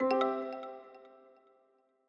Longhorn 8 - Message Nudge.wav